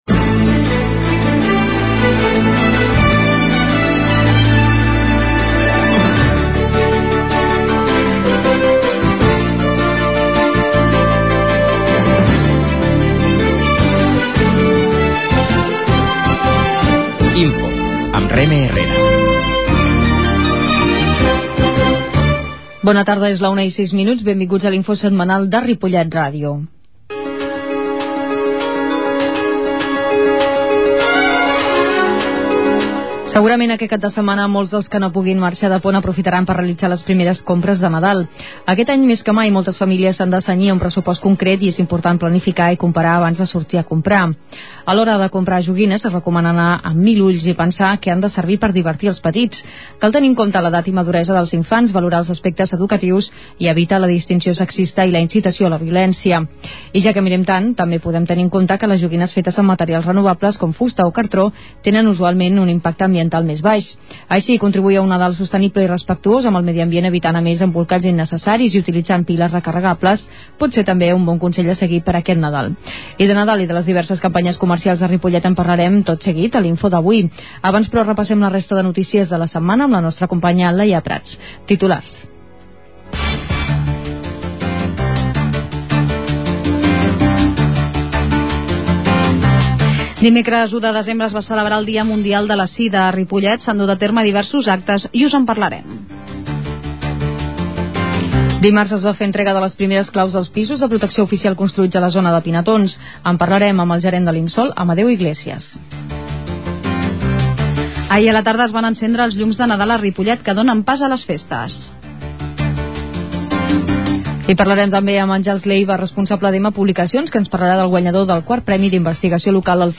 La qualitat de so ha estat redu�da per tal d'agilitzar la seva baixada.